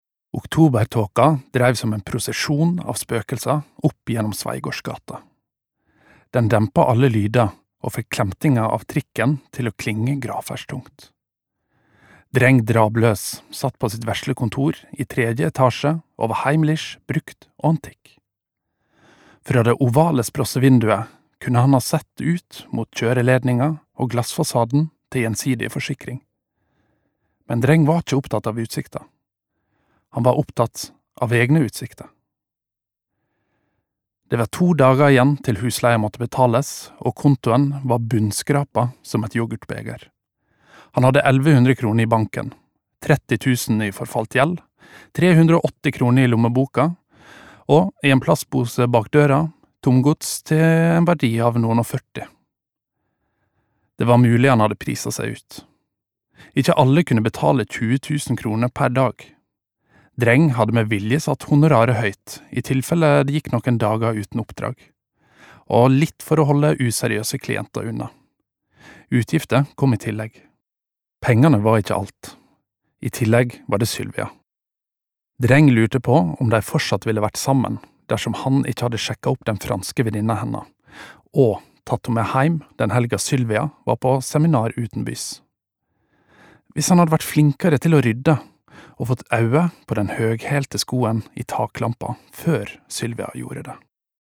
Nordfjord